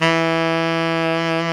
SAX TENORM05.wav